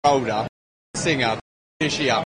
The recording of Princess Elizabeth exhibits this commA allophony, with a pre-pausal variant identical to the STRUT vowel, which for her was [ɐ].